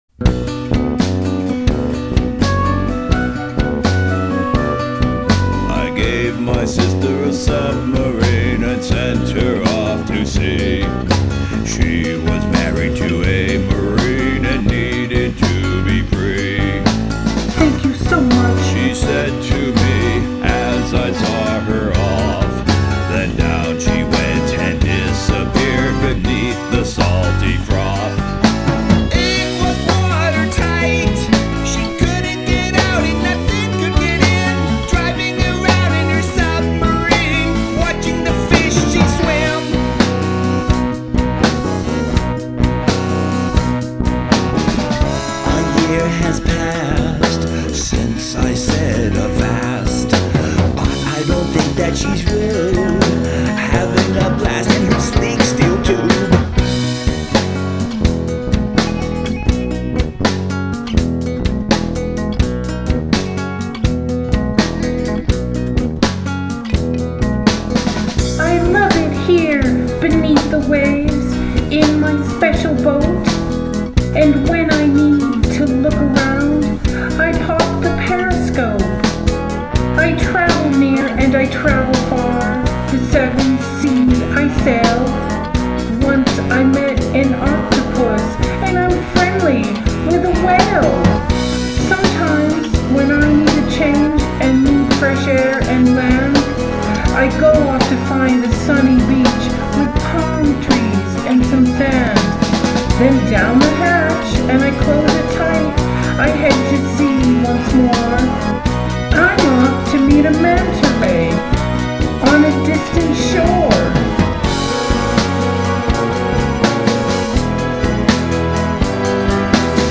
I like this one. it's loaded with quirky goodness.